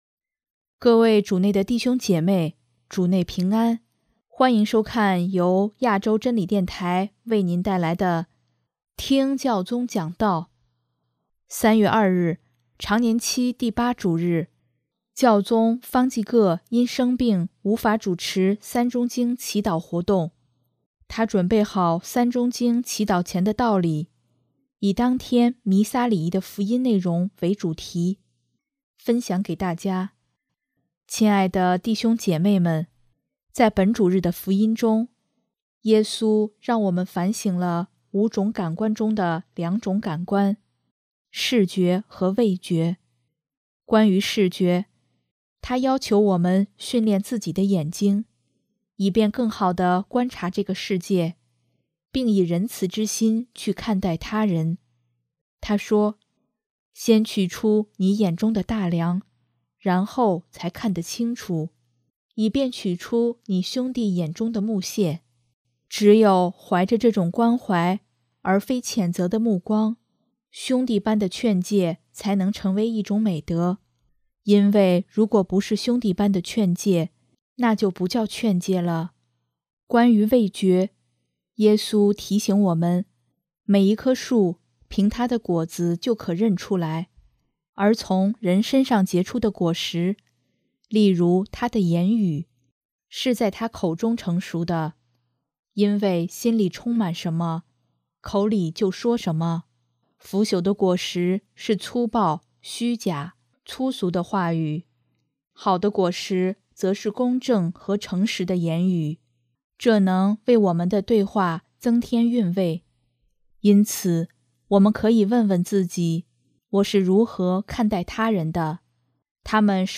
【听教宗讲道】|成熟的言语是好的果实